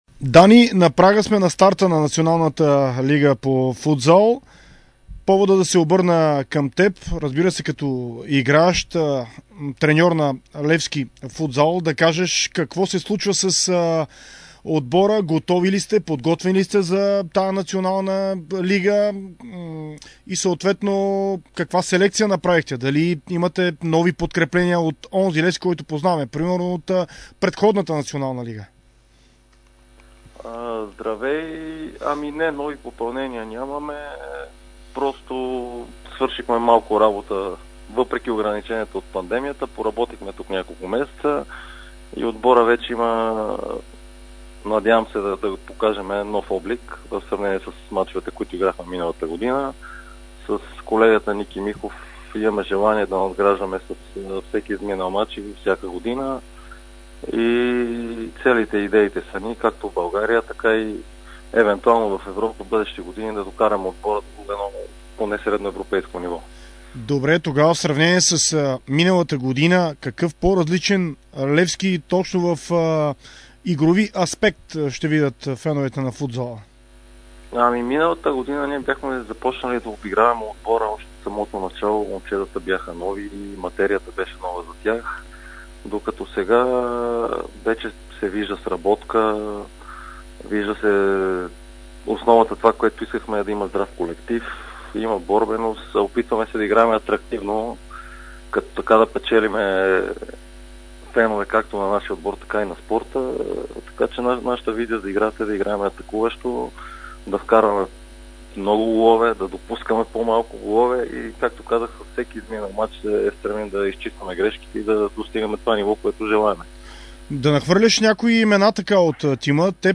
В интервю за Дарик радио и dsport